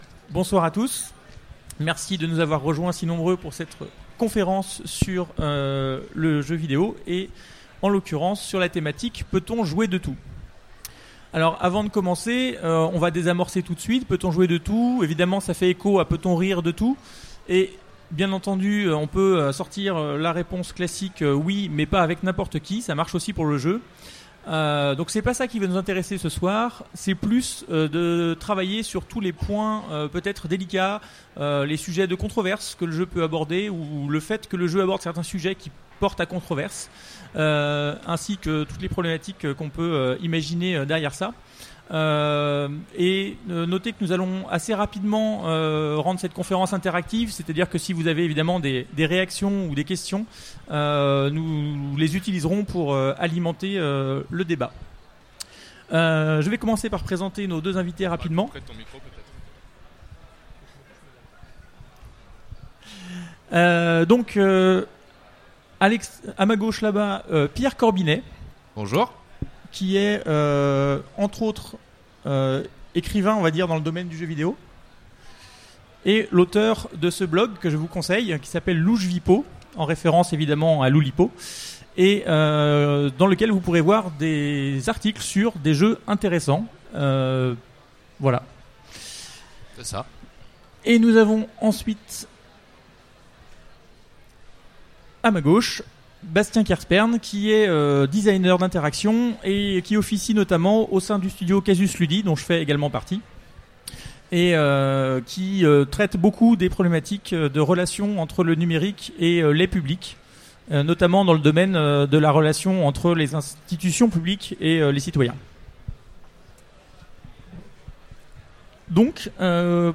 Utopiales 2016 : Conférence Peut-on jouer de tout ?